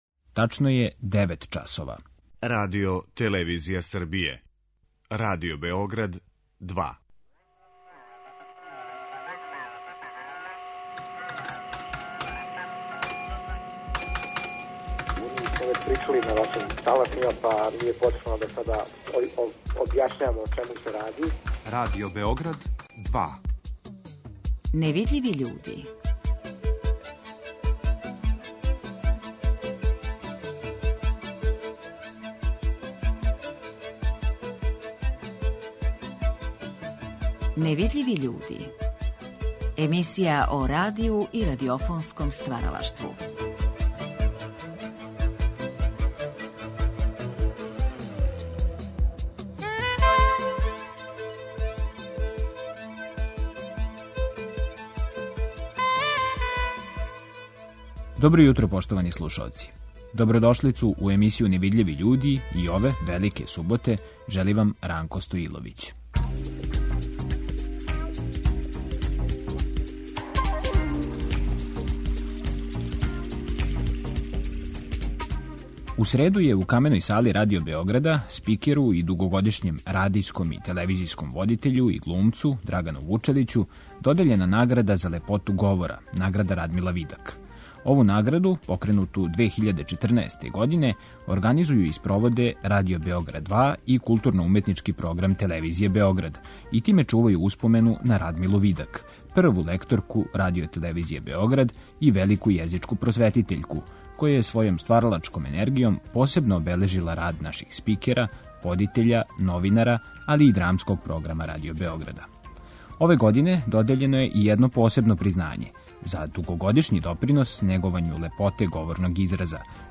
Ови разговори вођени су за циклус емисија „Гост Другог програма" 1975. године.